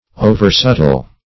Meaning of oversubtle. oversubtle synonyms, pronunciation, spelling and more from Free Dictionary.
Search Result for " oversubtle" : The Collaborative International Dictionary of English v.0.48: Oversubtile \O"ver*sub"tile\, Oversubtle \O"ver*sub"tle\, a. Excessively subtle.